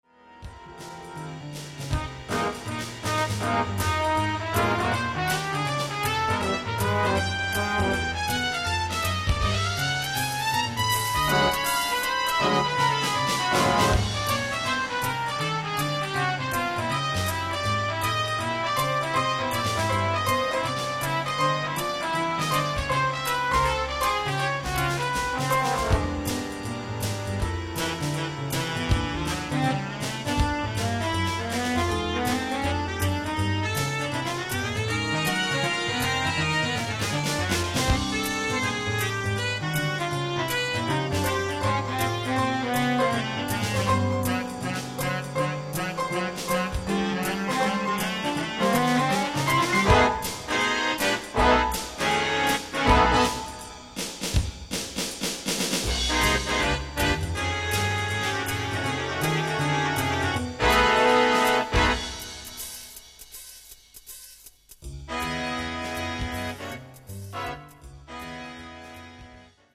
big band